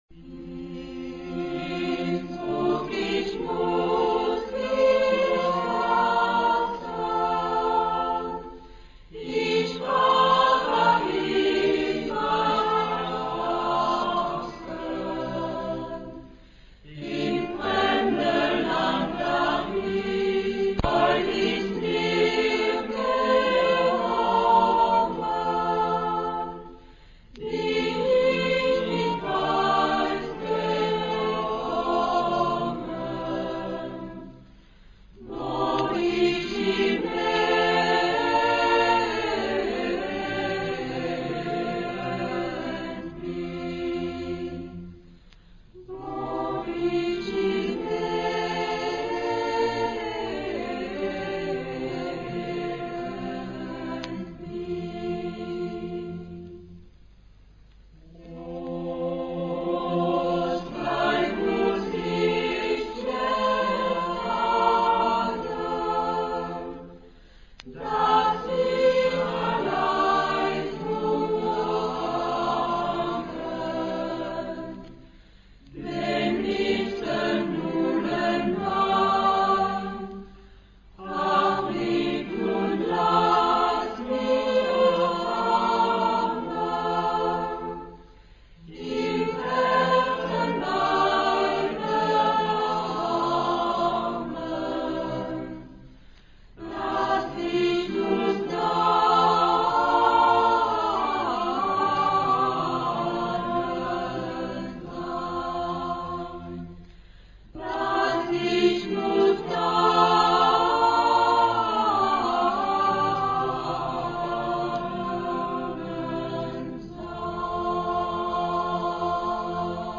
Epoque: 15 ; 16th century
Genre-Style-Form: Popular ; Renaissance ; Secular
Type of Choir: SATB  (4 mixed voices )